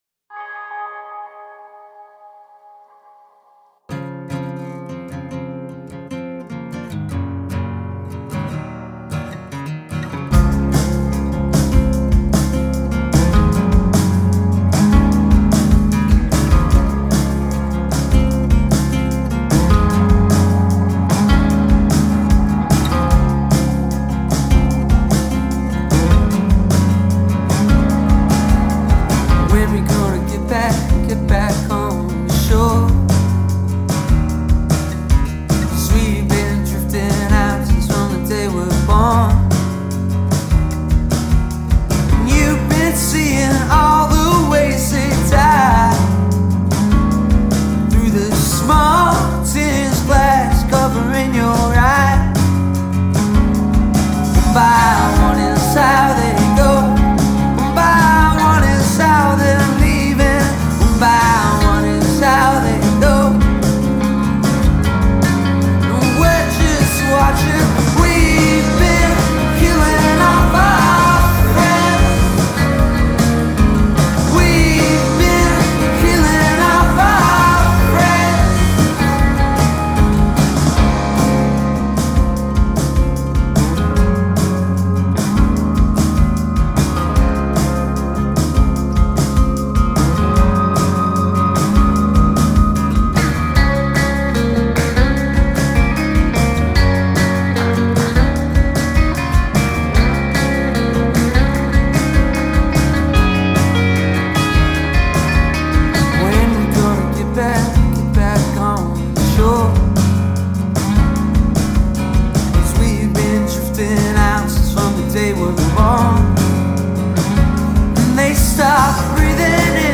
Genre: Britpop